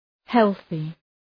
Προφορά
{‘helɵı}